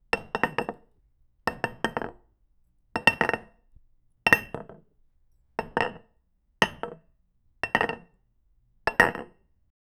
placing ceramic cups on wooden counter, soft clinks and slides
placing-ceramic-cups-on-w-sbfy6grf.wav